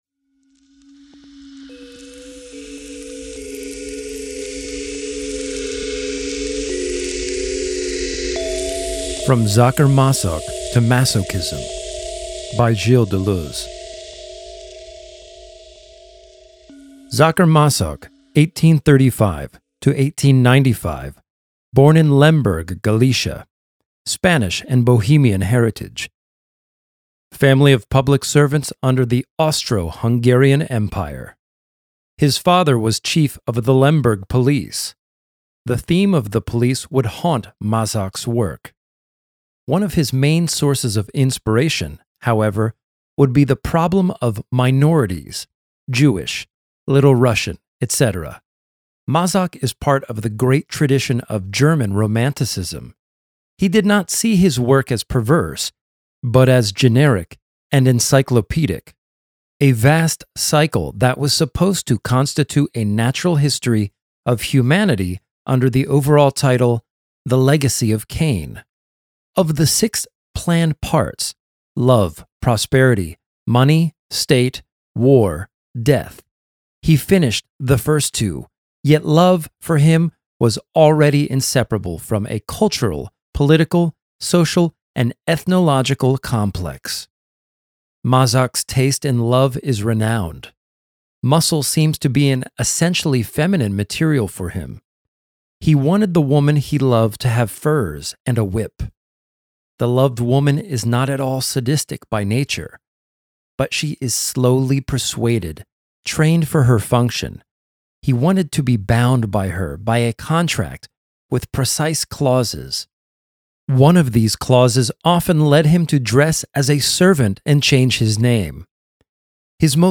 In any event, enjoy this patron exclusive reading!